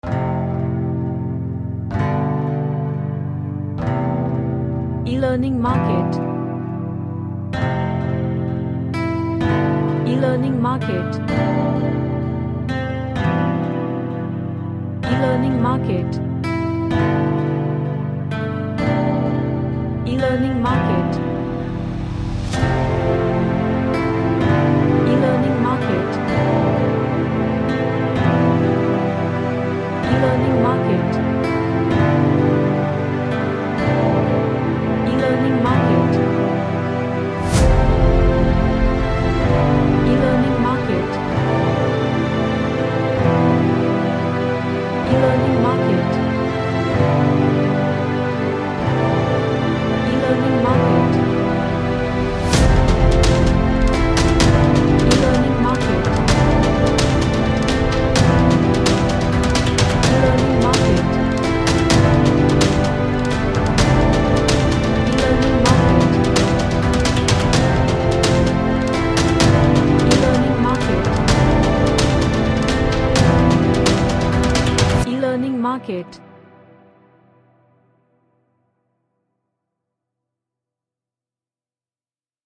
An electric guitar based stringed track.
Energetic